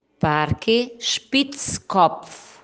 Pronúncia: Is-píts-cóp-f